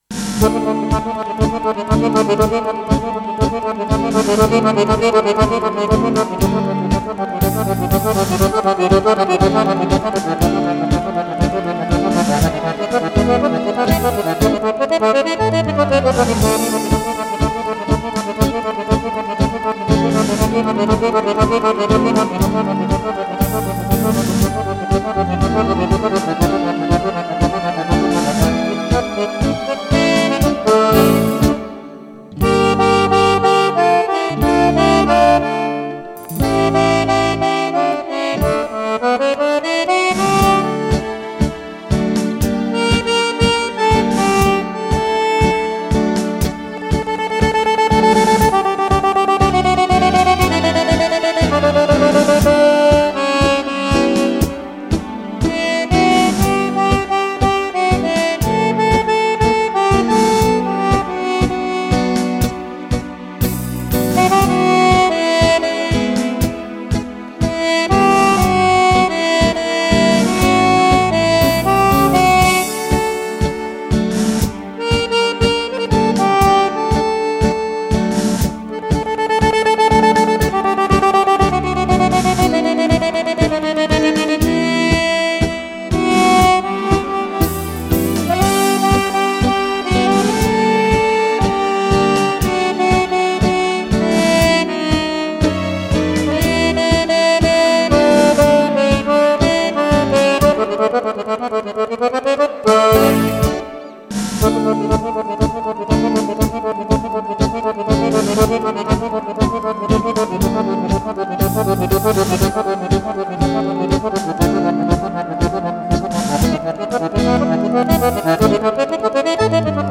Tango
ballabili per sax e orchestra stile Romagnolo.